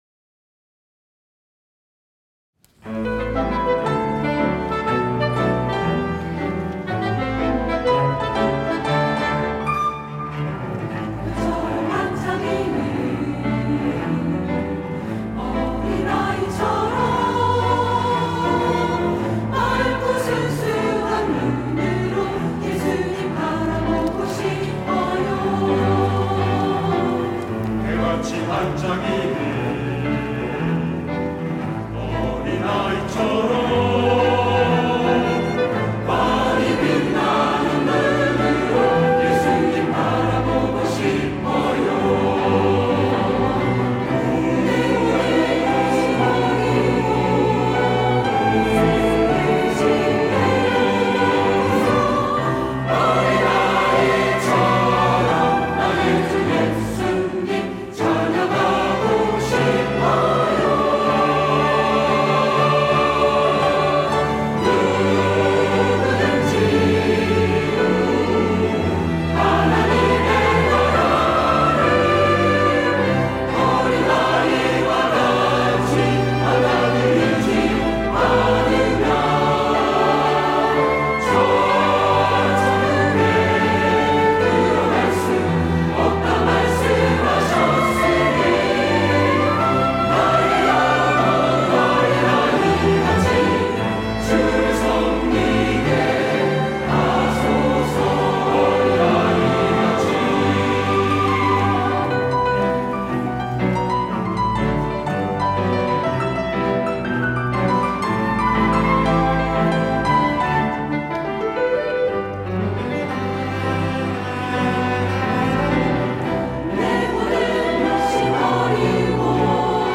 호산나(주일3부) - 어린아이와 같이
찬양대